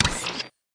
Store Trade Channel Sound Effect